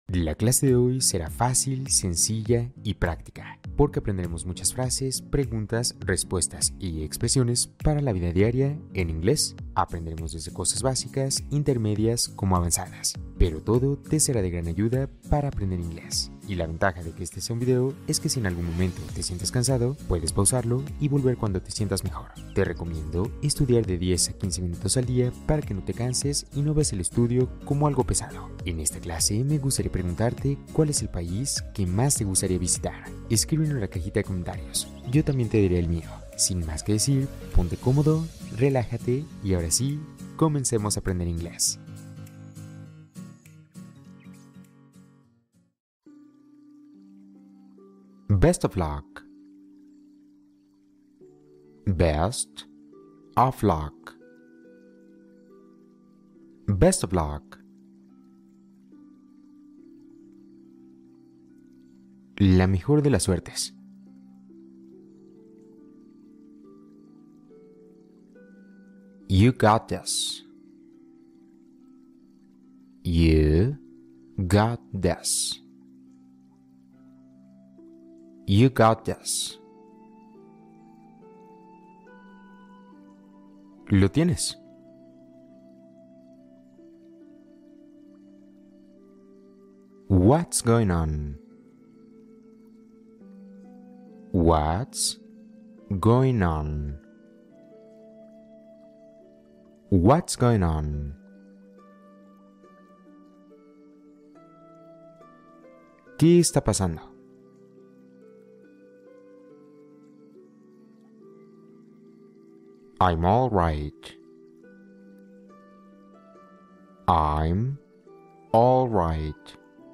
La vida diaria en inglés | Listening fácil y lento para entrenar tu oído